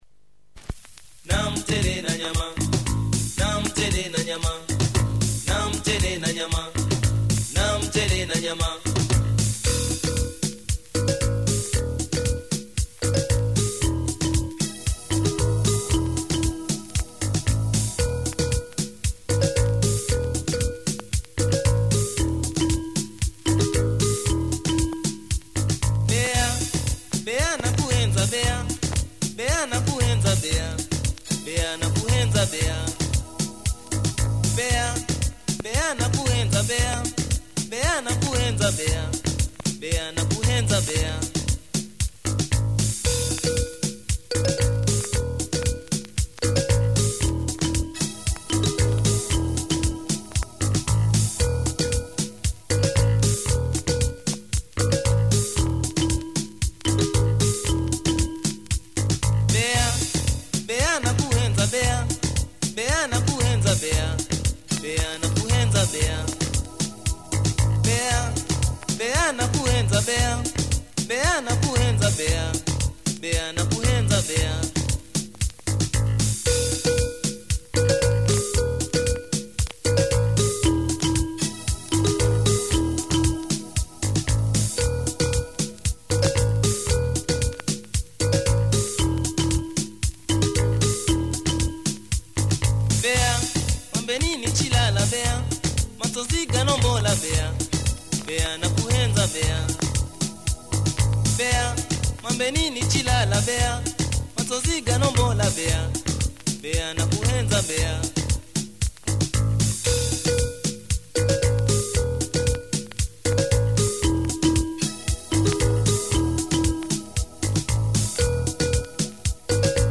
afro synth minimalism